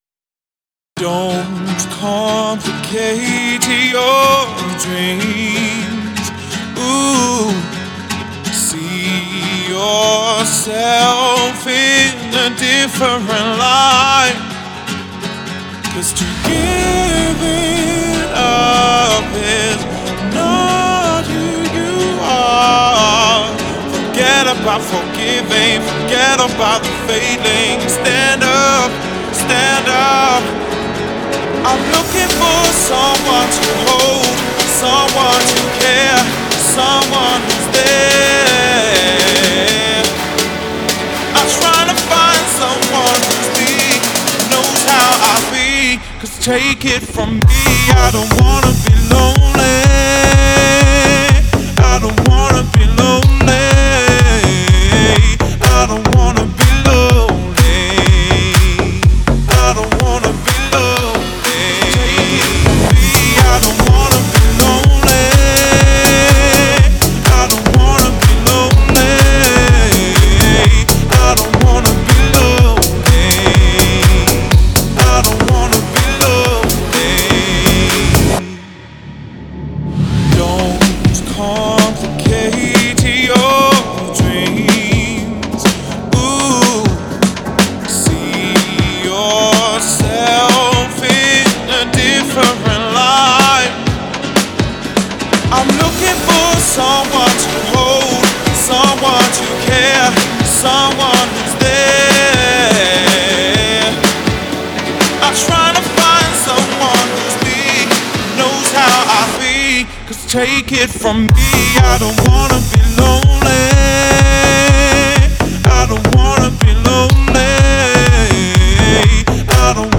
мелодичная поп-песня
наполненная эмоциями и искренностью.
теплым вокалом и яркими гармониями